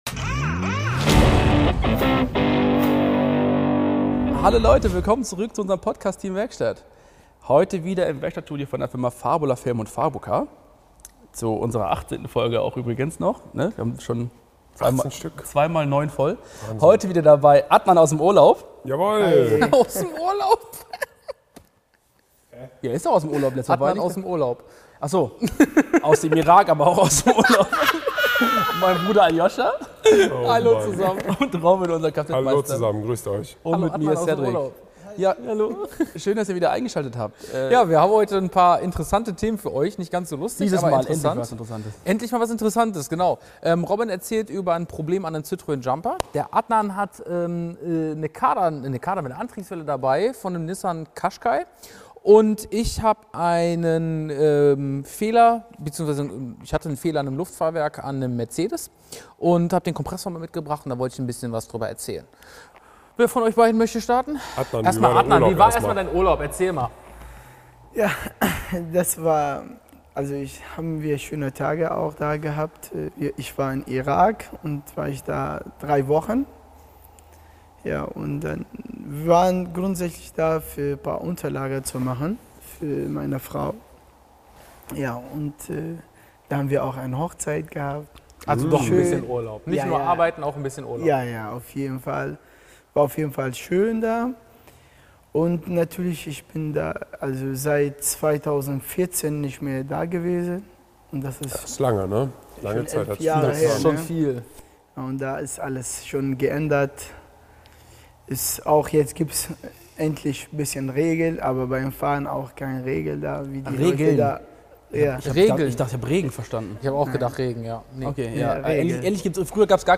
#18 KOPFSCHÜTTELN ~ TEAM WERKSTATT | Der Feierabend-Talk aus der Werkstatt der Autodoktoren Podcast